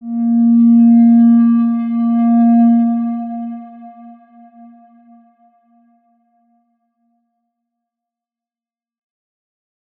X_Windwistle-A#2-pp.wav